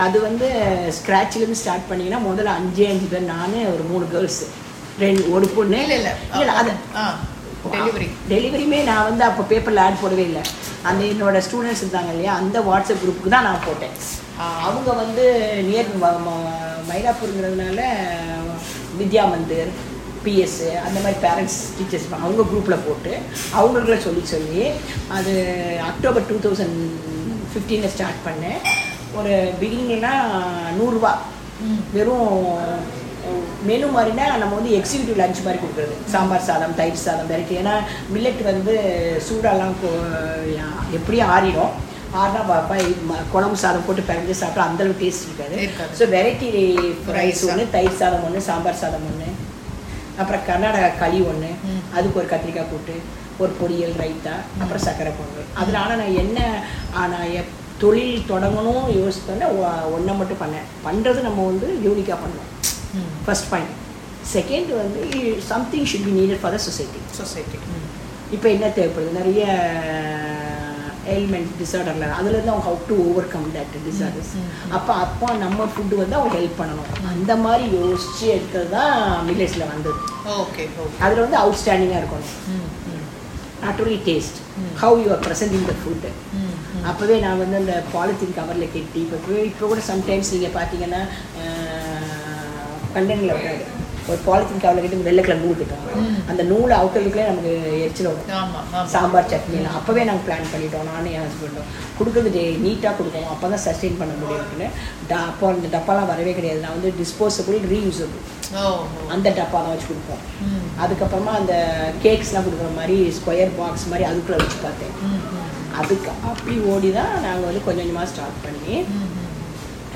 நேர்காணல்கள்